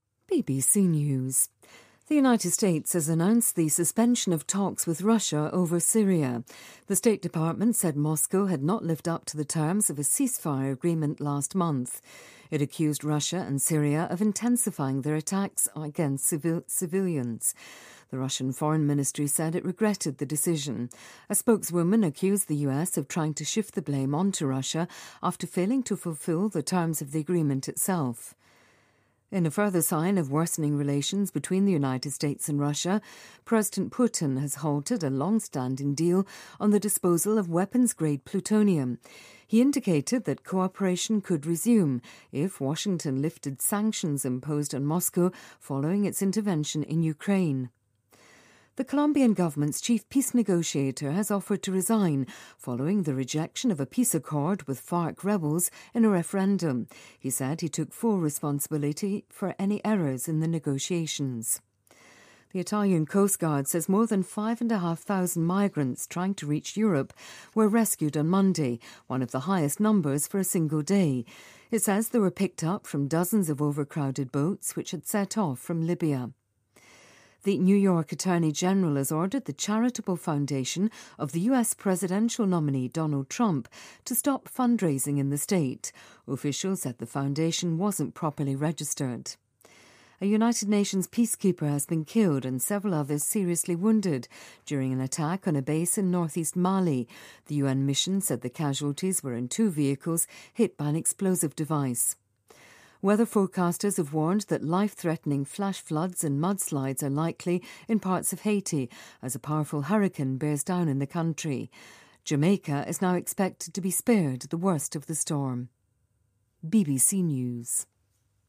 BBC news,美俄关系或恶化